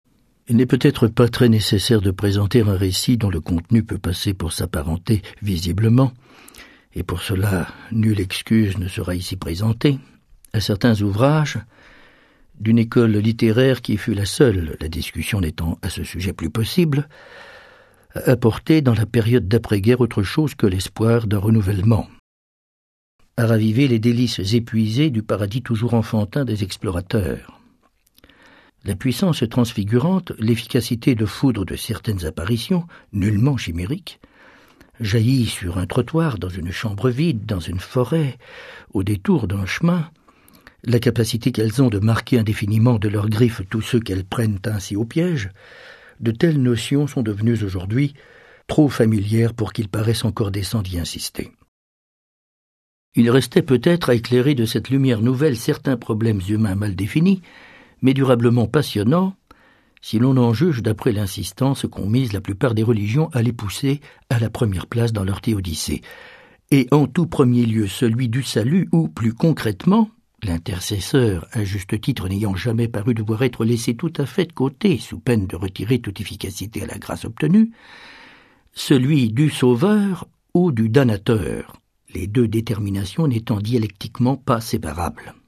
Extrait gratuit - Au château d'Argol de Julien Gracq
0% Extrait gratuit Au château d'Argol de Julien Gracq Éditeur : Brumes de mars Paru le : 2009 Lu par Bernard-Pierre Donnadieu Julien Gracq (1910-2007)est un des écrivains majeurs de la littérature du XXe siècle. Au château d'Argol, son premier roman, parut en 1939 grâce à l'éditeur José Corti.